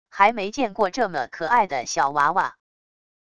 还没见过这么可爱的小娃娃wav音频生成系统WAV Audio Player